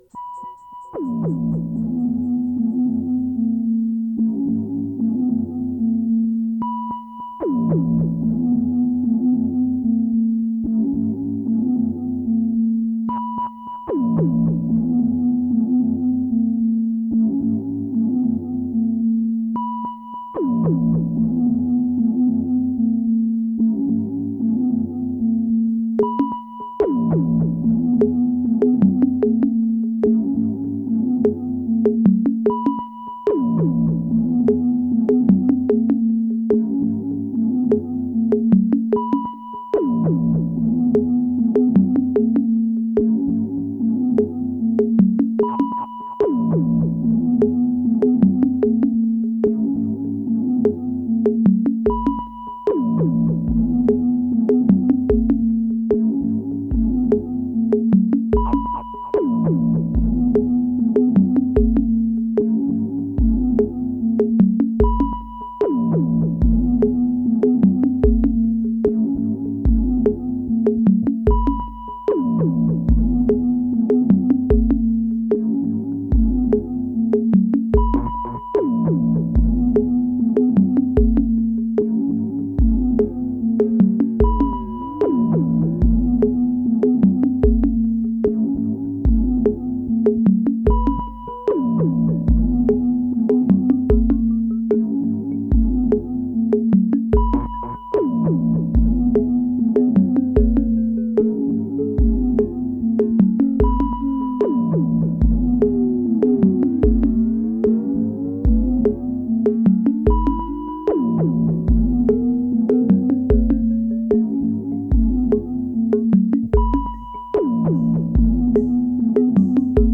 Genre: Dub, Downtempo, Ambient.